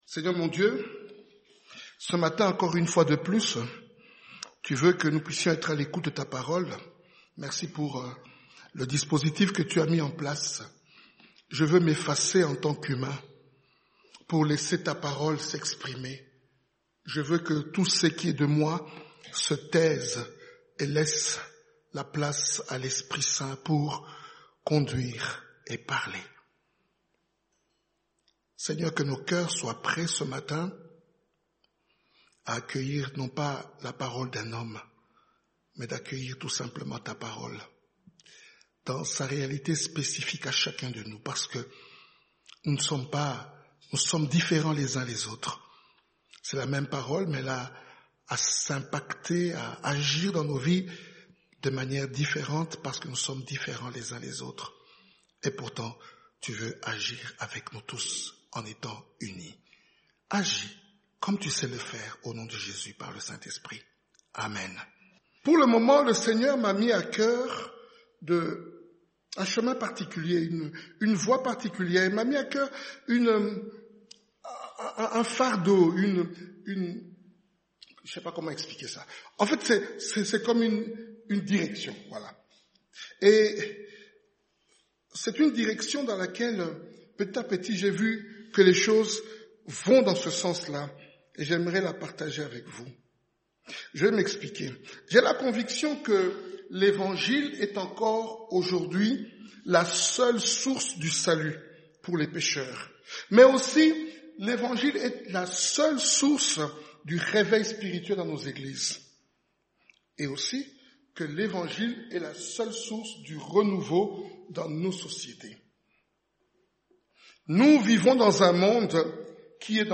Type De Service: Dimanche matin